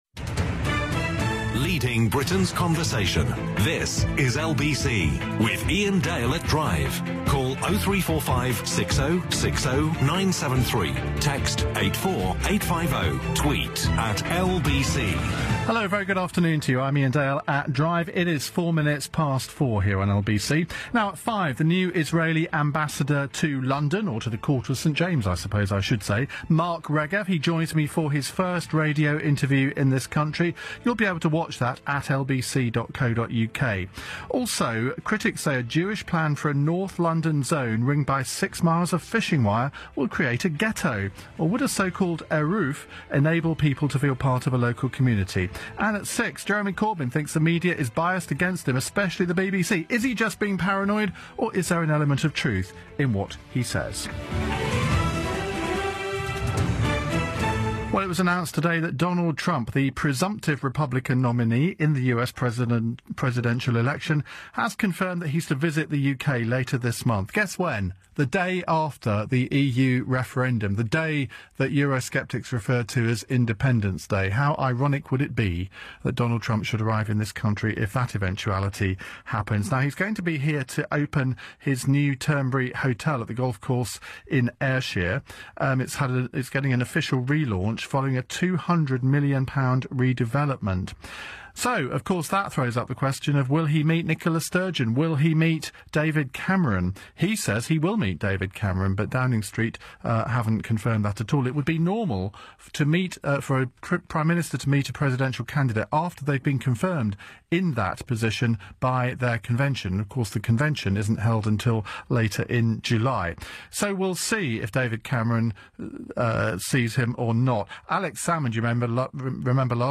live on LBC's "Iain Dale at Drive" programme.